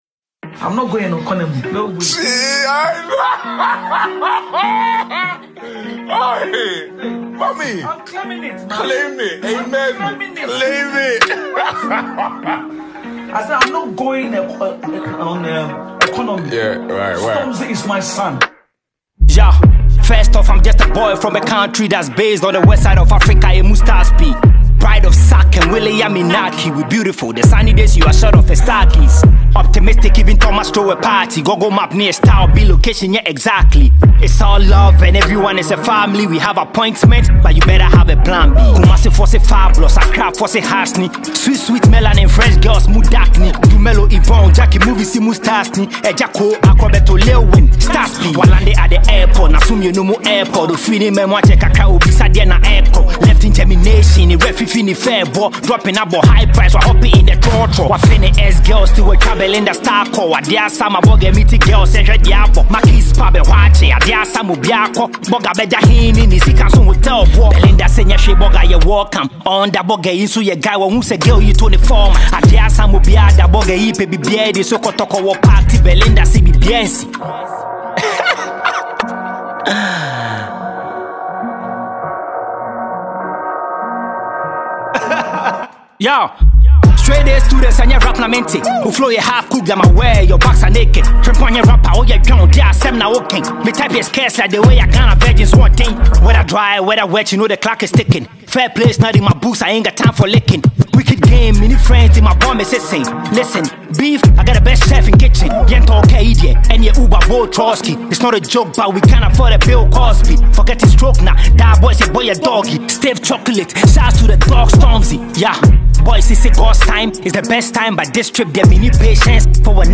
hardcore rap song